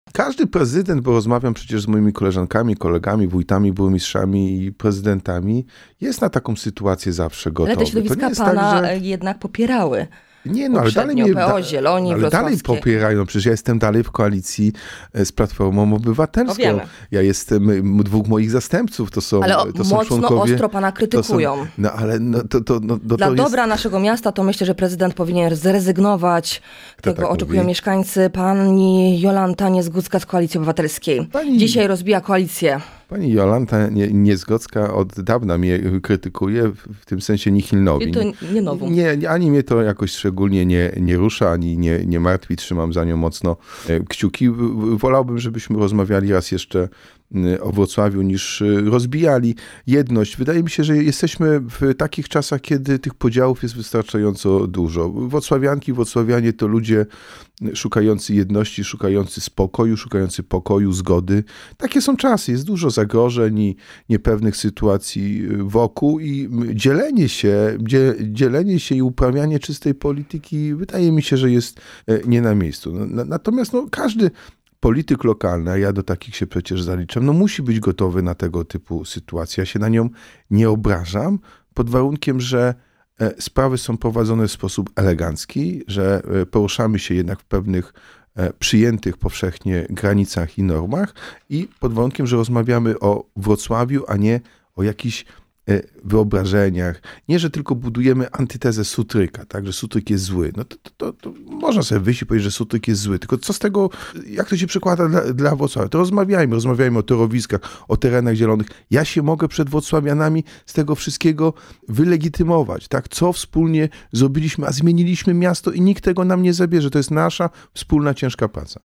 Każdy, kto będzie chciał to porozumienie rozbijać, rozszarpywać, może być winny złego wyniku w wyborach. – mówił w „Porannym Gościu” Jacek Sutryk – prezydent Wrocławia.